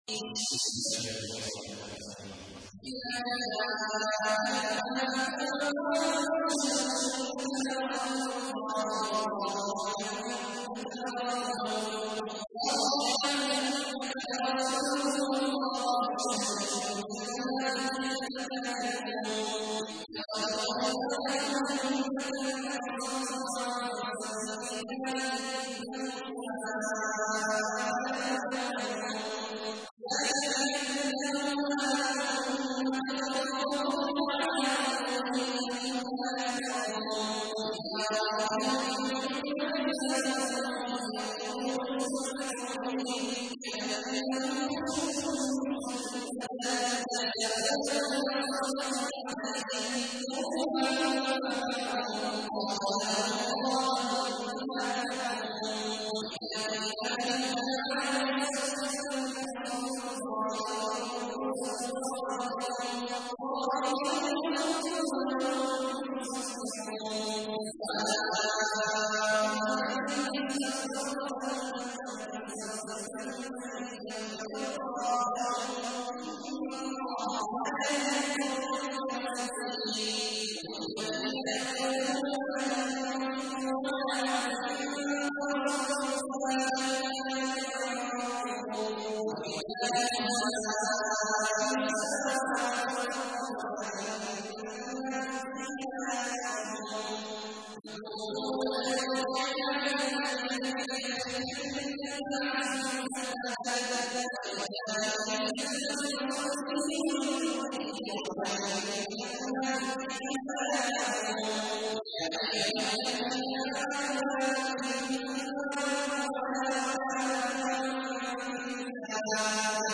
تحميل : 63. سورة المنافقون / القارئ عبد الله عواد الجهني / القرآن الكريم / موقع يا حسين